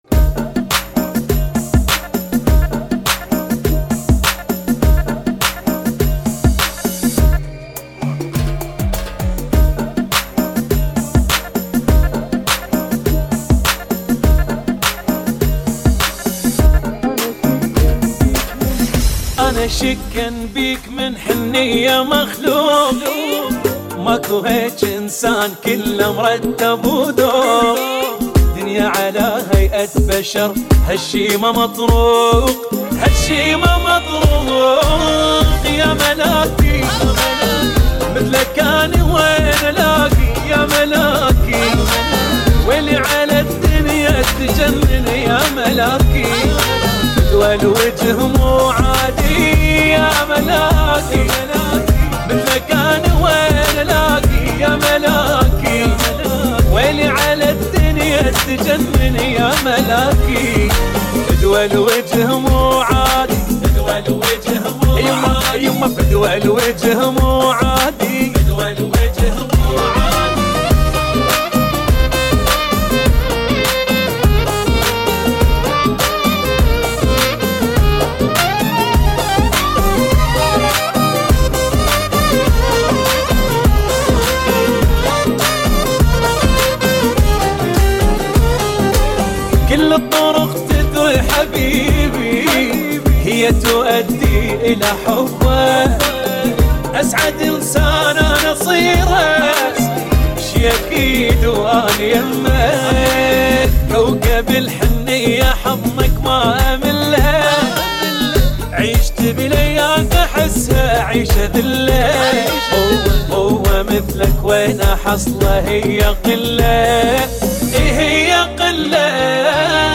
[ 102 bpm ] 2022